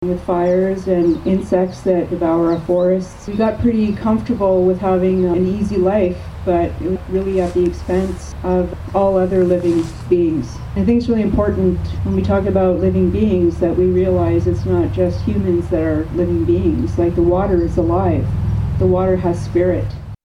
After about an hour of placard-waving surrounding the junction of Highways 60 and 41, the group retired to the serenity of the amphitheatre in Gerald Tracey Park to hear from a variety of speakers including one pragmatic Upper Tier radical.